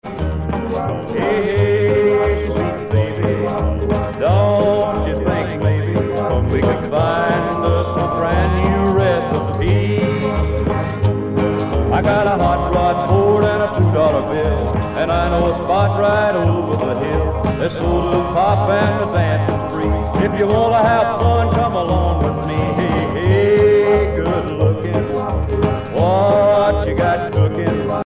danse : rock
Pièce musicale éditée